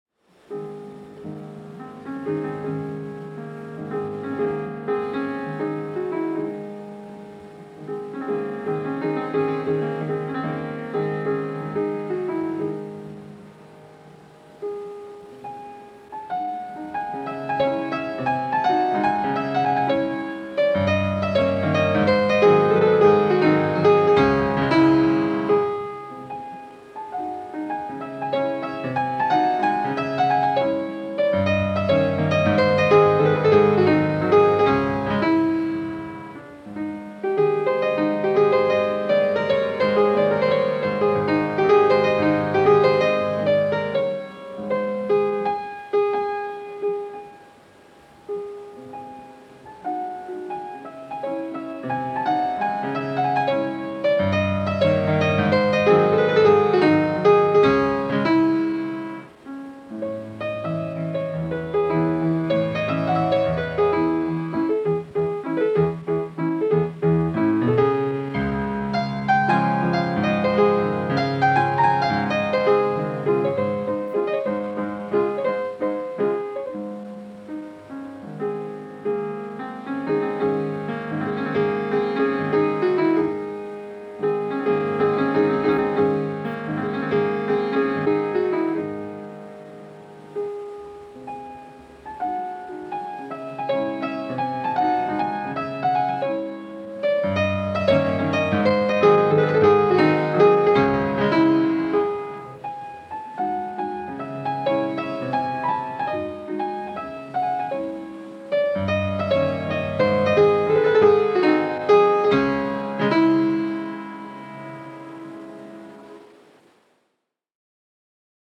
en vivo, año 1982
en fa sostenido menor
piano